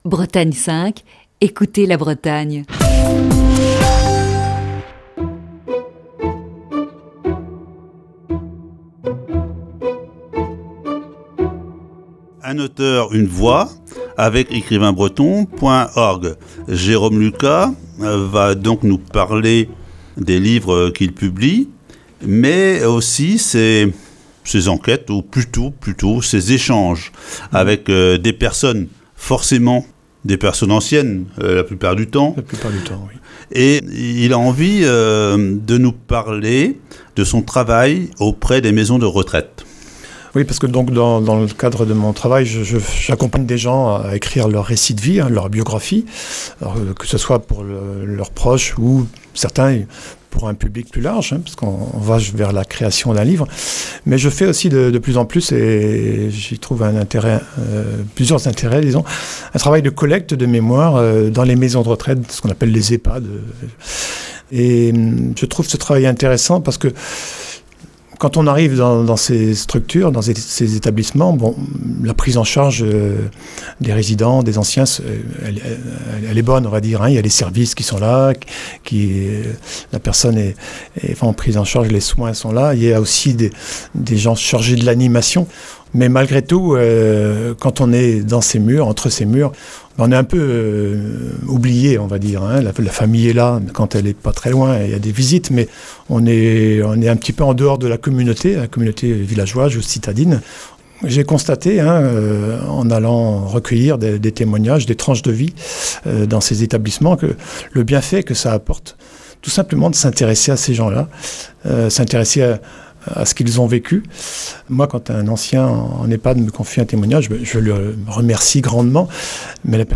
Un Auteur, Une Voix. Voici ce jeudi la quatrième partie de cette série d'entretiens.